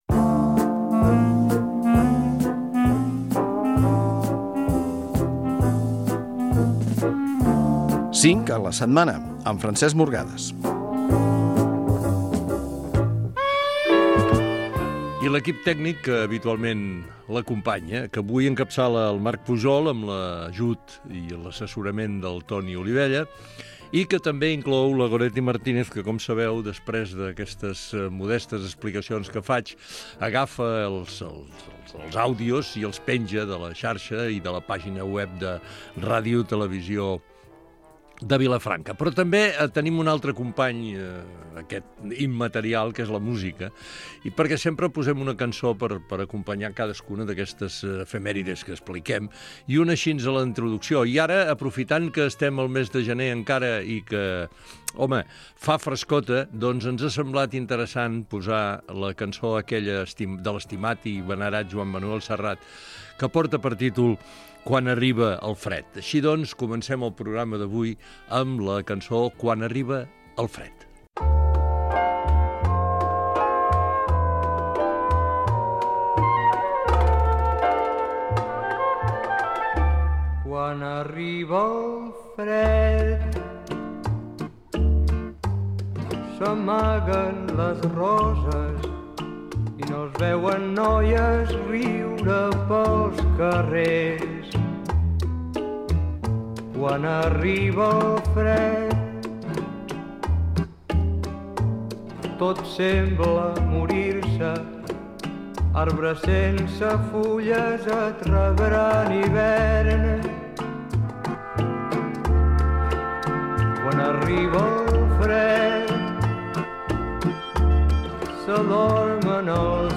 Efemèrides i música